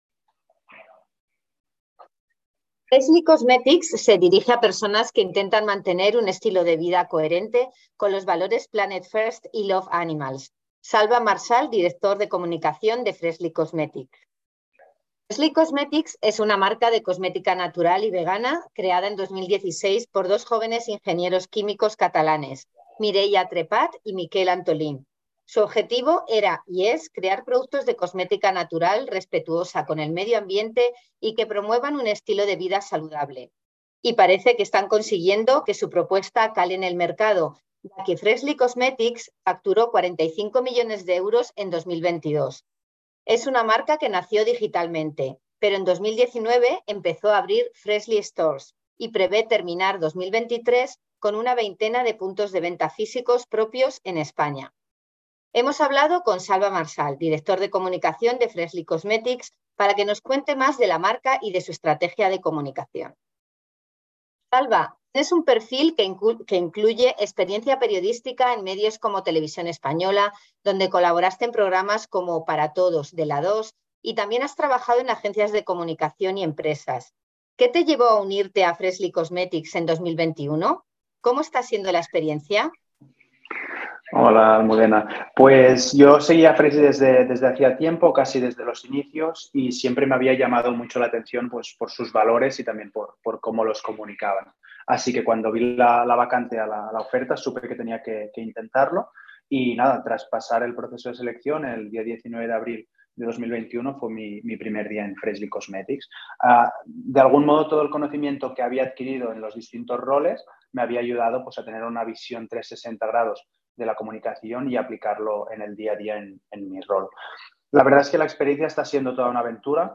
FreshlyCosmeticsEntrevista.mp3